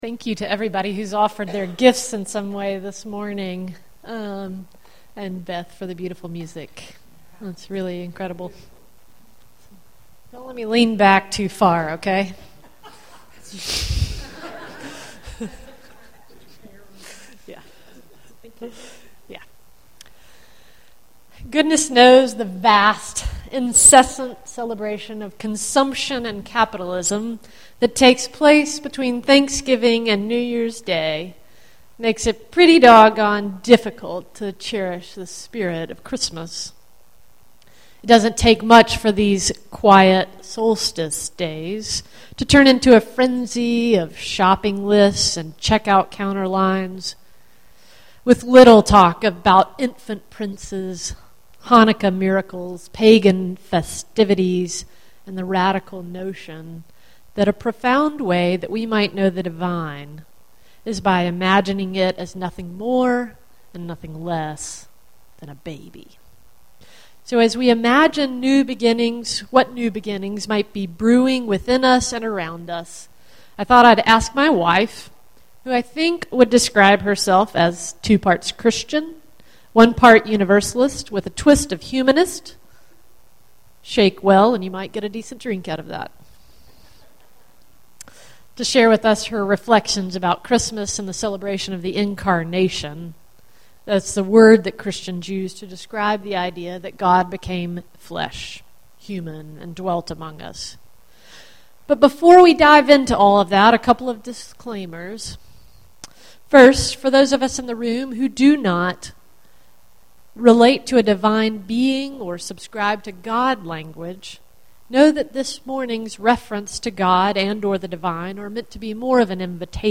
As we gather this holiday morning, we will consider the mystery of birth and new beginnings that spans time and beyond religion.